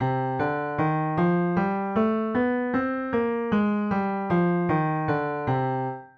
c-melodic-minor-scale.mp3